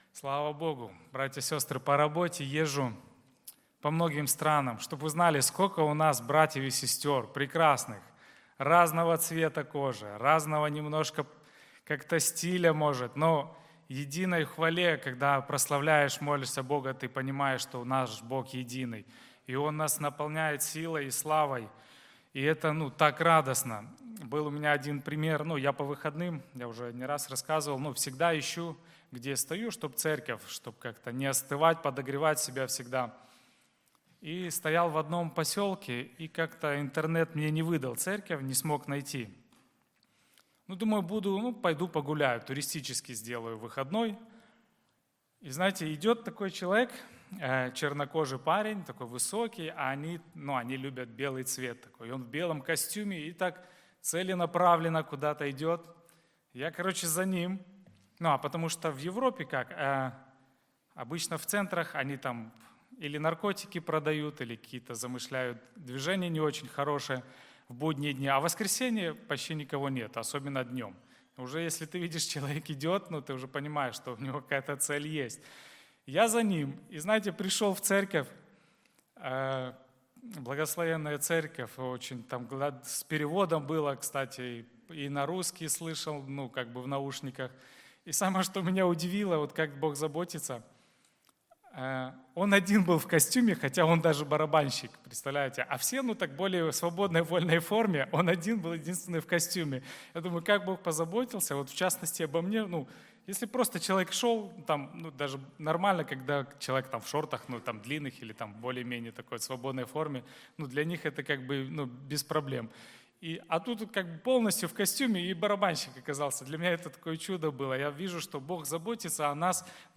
Church4u - Проповеди